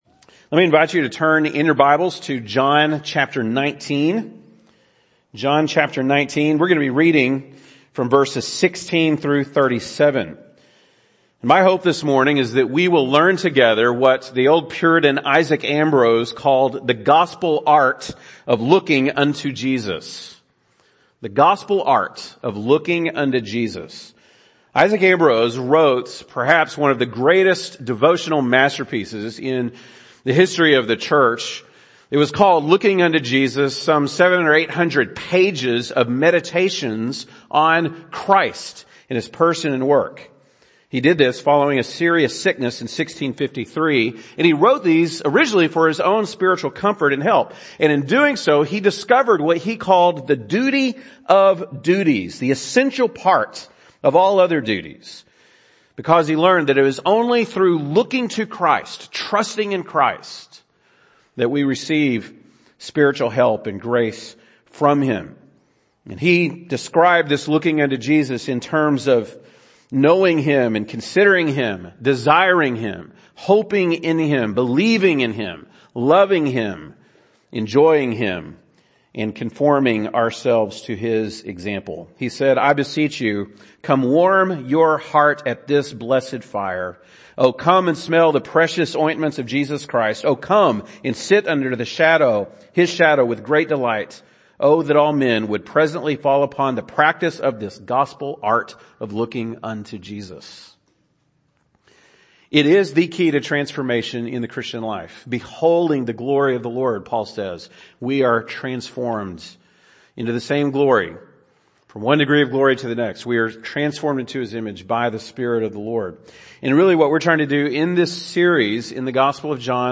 April 10, 2022 (Sunday Morning)